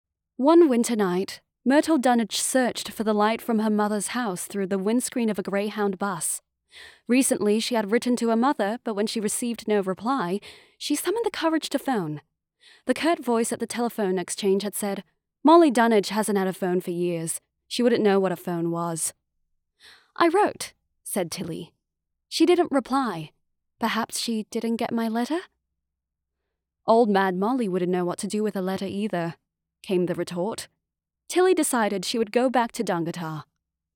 Young Adult, Adult
australian | natural